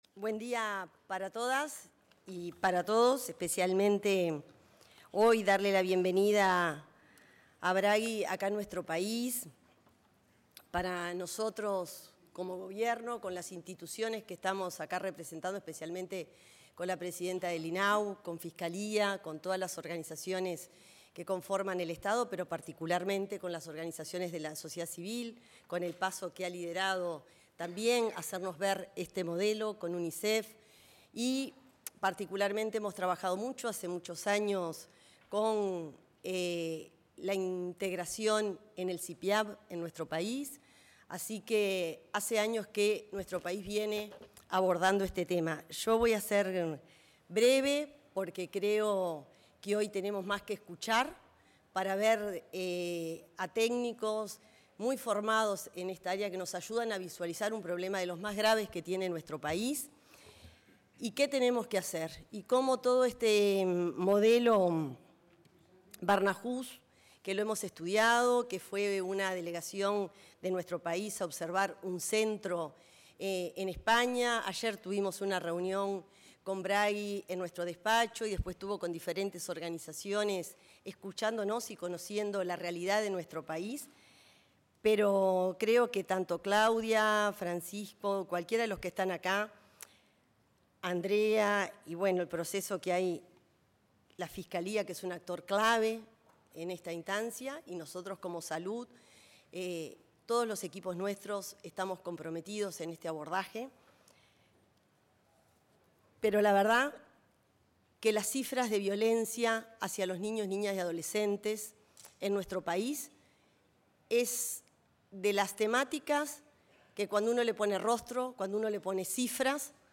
Palabras de autoridades en seminario sobre modelo de atención Barnahus
La ministra de Salud Pública, Cristina Lustemberg, y la presidenta del Instituto del Niño y el Adolescente del Uruguay, Claudia Romero, se expresaron